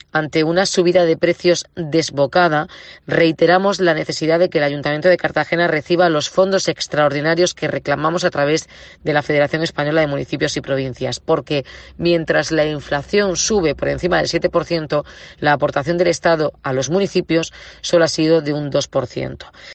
La alcaldesa de Cartagena ha participado en la Junta de Gobierno de la FEMP reunida en Estepona
Declaraciones de Arroyo en la FEMP